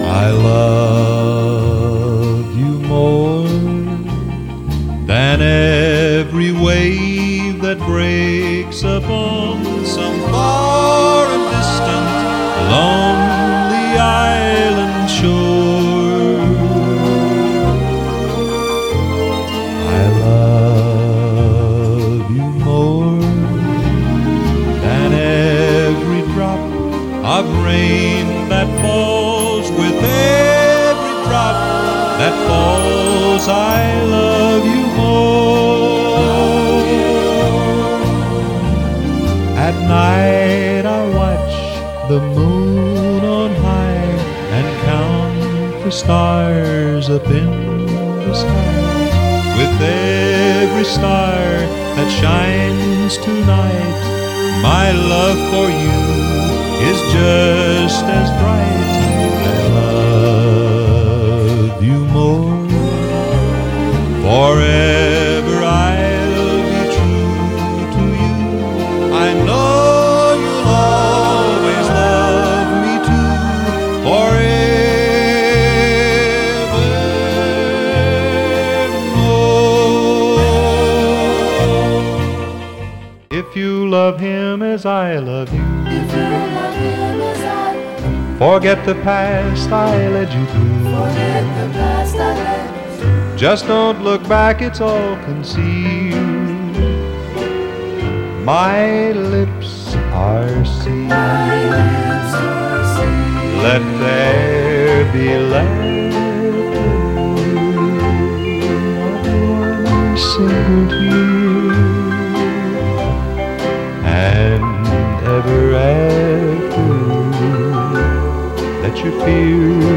The richness of the sound will astonish you.